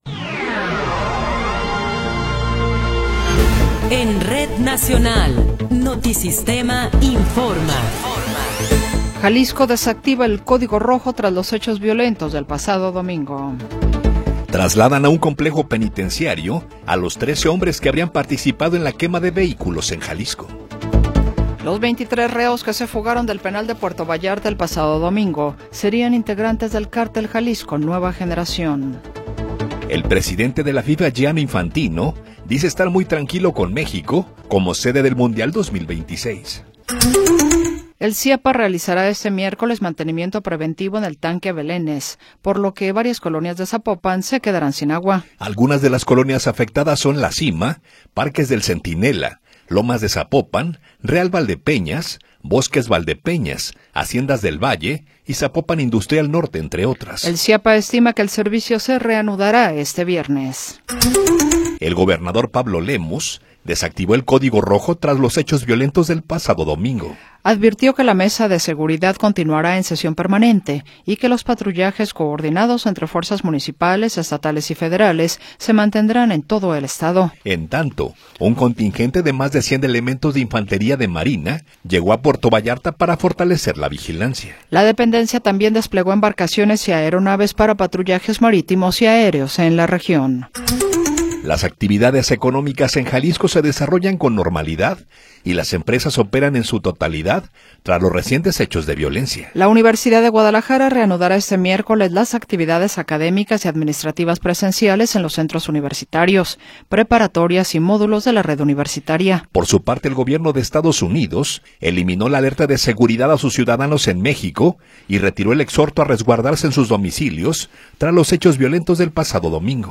Noticiero 20 hrs. – 24 de Febrero de 2026